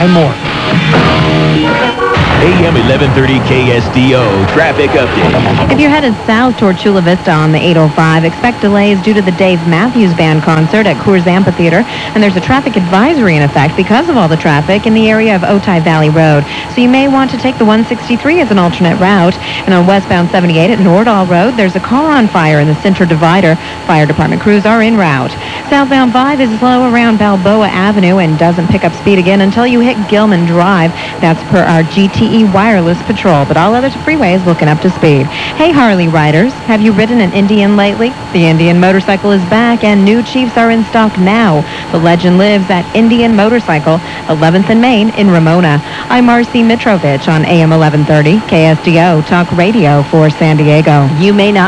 radio stations
traffic.wav